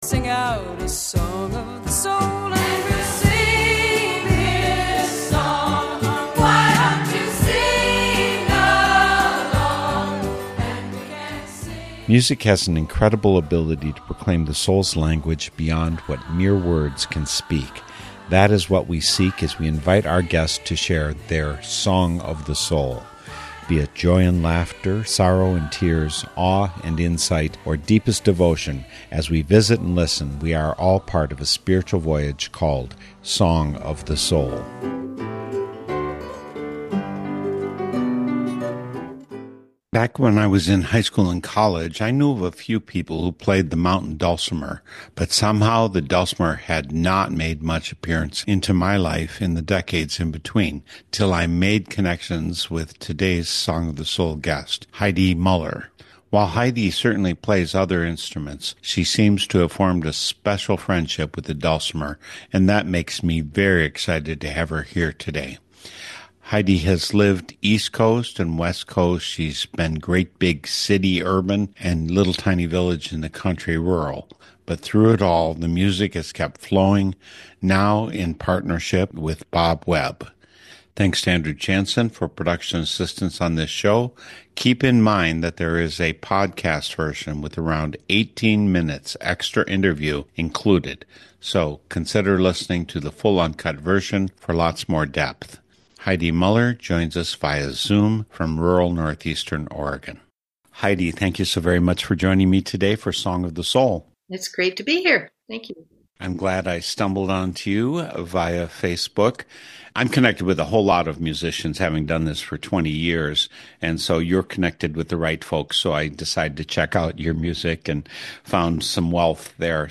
That's what we seek as we invite our guests to share their Song of the Soul. You will hear the music that has charted the steps of their spiritual journey,…